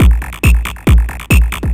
DS 138-BPM A5.wav